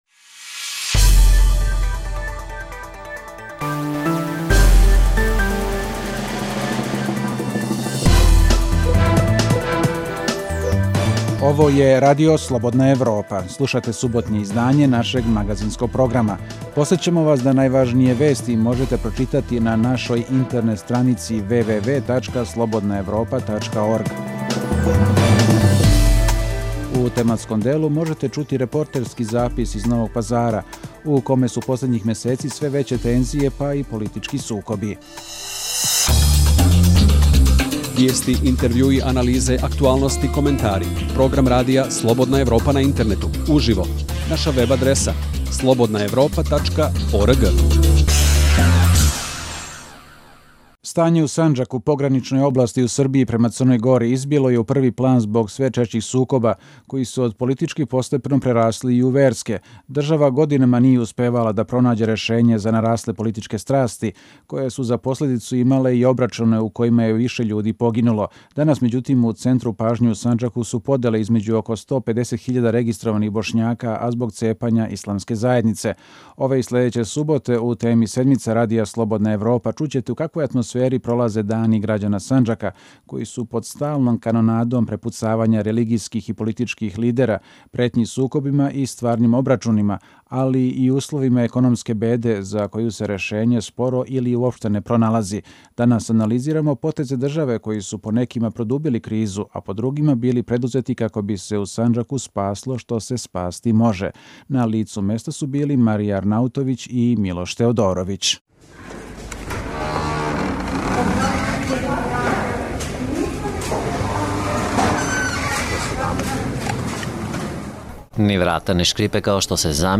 Reportaža iz Sandžaka (1. dio)